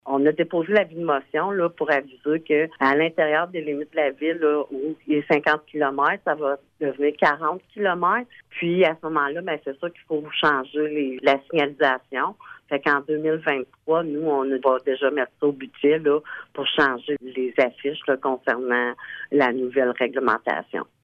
Après plusieurs rencontres avec des intervenants de la sécurité routière tels que la Sureté du Québec (SQ) et le ministère des Transports du Québec (MTQ), il a été recommandé que la vitesse soit abaissée de 50 km/h à 40 km/h dans les limites de la Ville. La mesure, qui est déjà mise de l’avant ailleurs au Québec, sera appliquée en 2023 à Maniwaki, comme nous l’explique la mairesse, Francine Fortin :